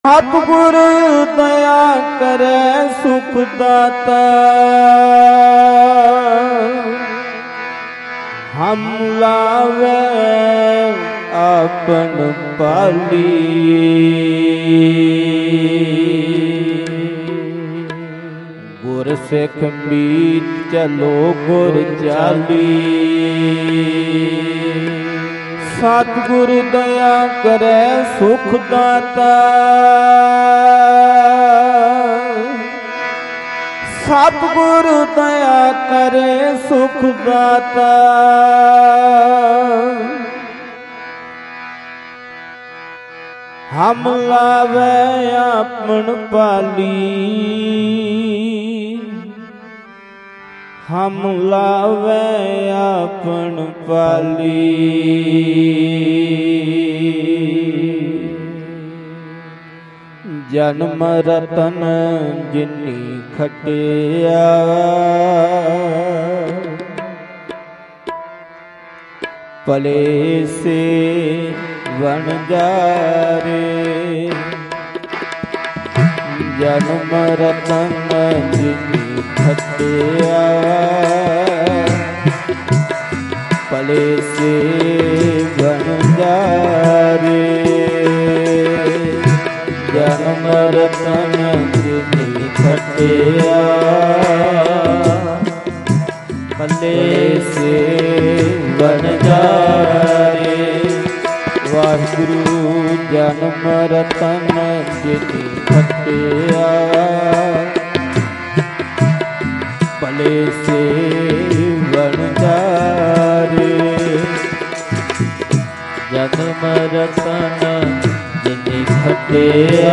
Mp3 Diwan Audio by Bhai Ranjit Singh Ji khalsa Dhadrian wale at Parmeshardwar